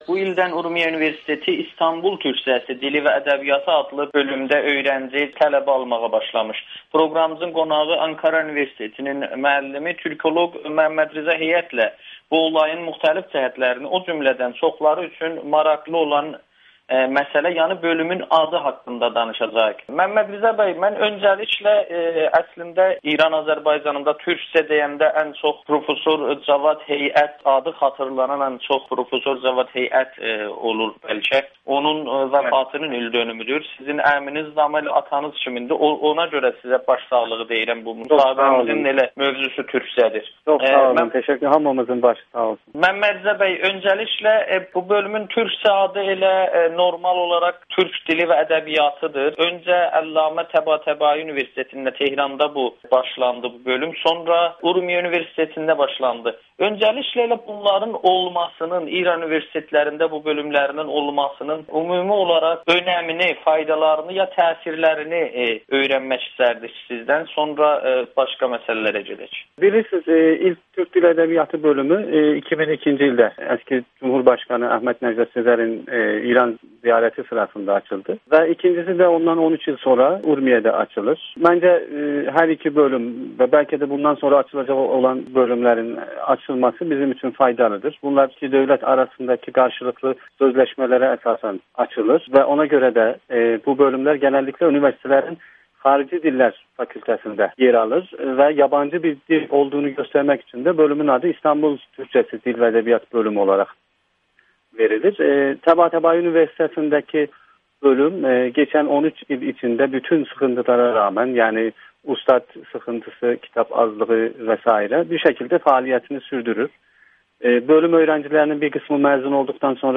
Güneydə ‘meyar dil’ - ‘Təbriz ağzı’ və ‘İstanbul Türkcəsi’ mövzuları [Audio-Müsahibə]